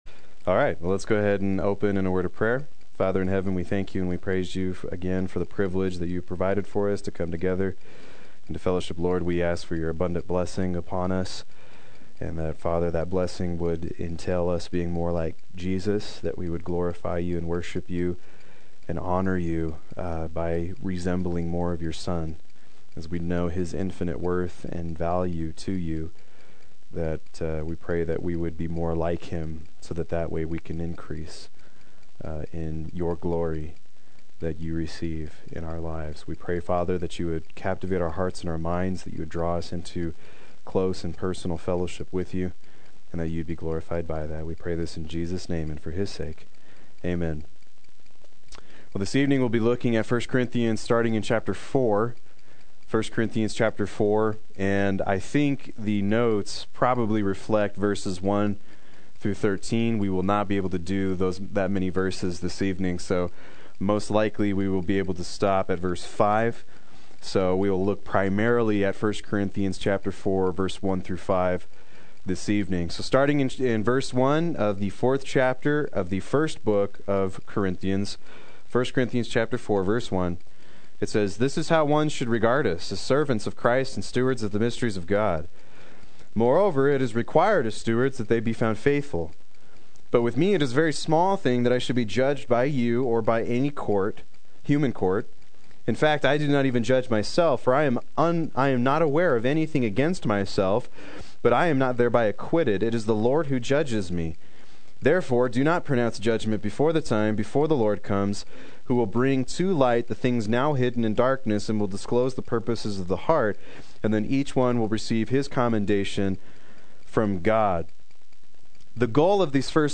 Proclaim Youth Ministry - 03/05/16
Play Sermon Get HCF Teaching Automatically.